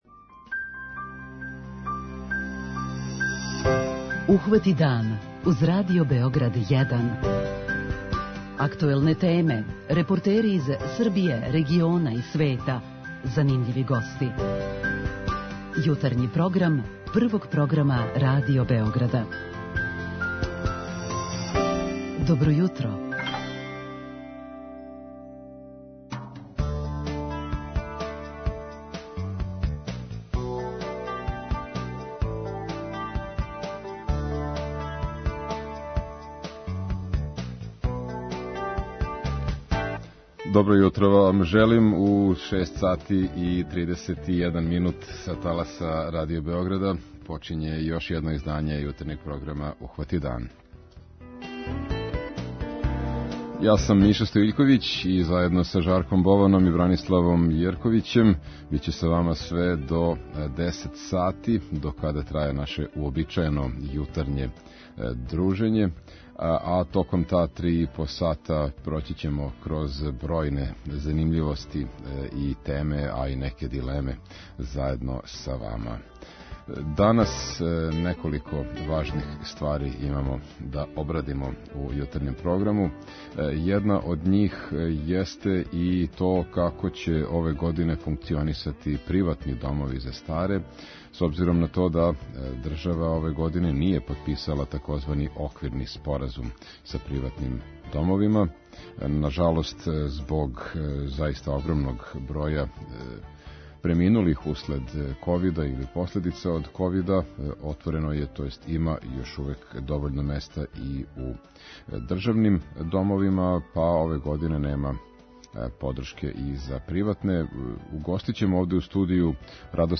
У јутарњем програму говорићемо и о још две важне теме.